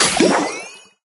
safe_open_elixir_01.ogg